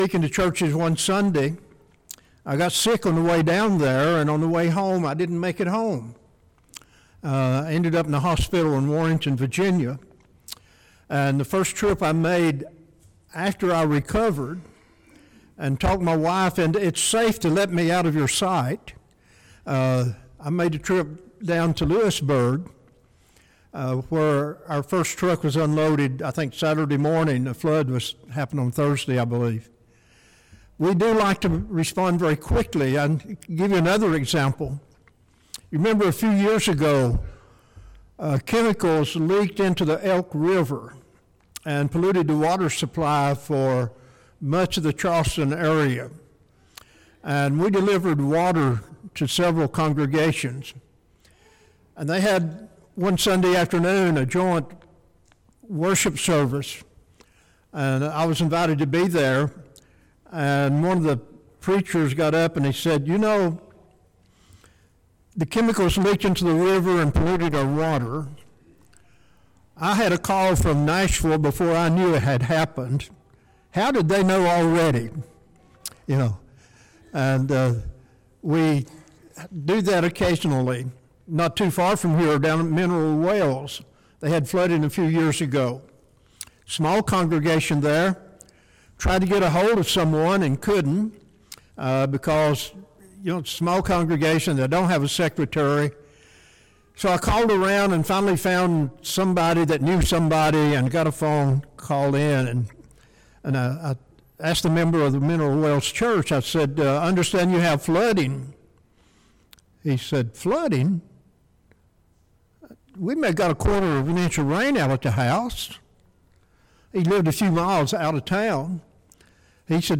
Disaster Relief Sermon